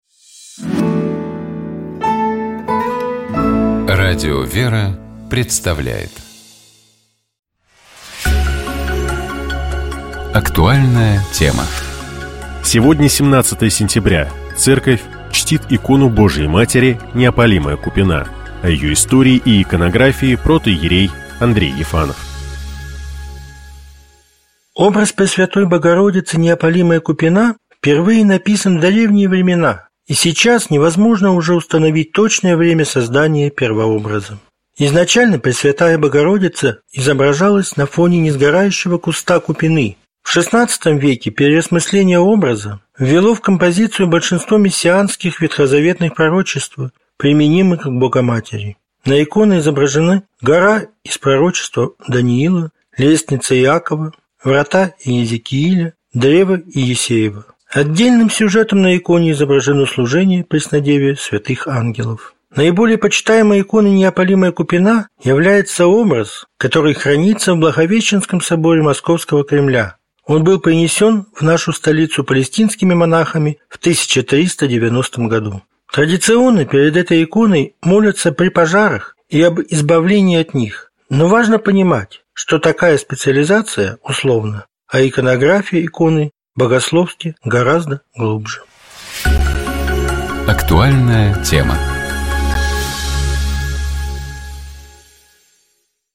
Разговор шел об особенностях православных календарей, о том, почему центральным праздником церковного года стала Пасха, а также о том, как Воскресение Христово встречают и отмечают на Афоне.